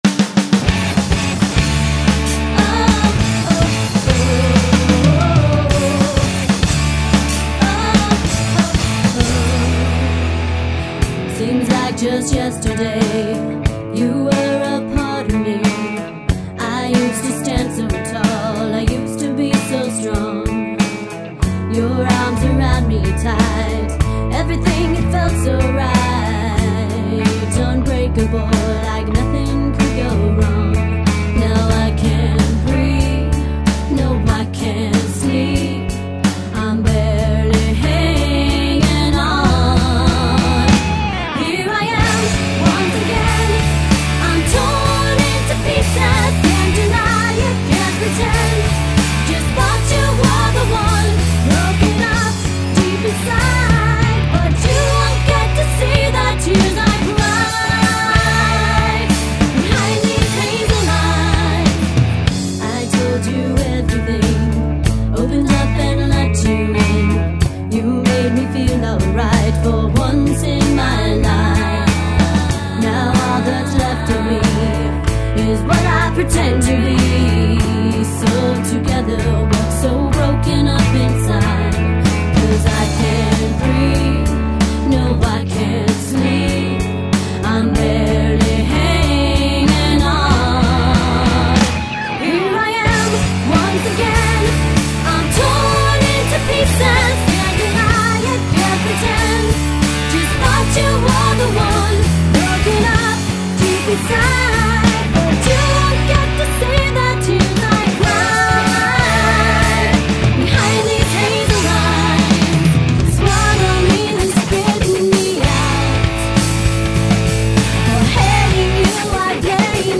Live Music!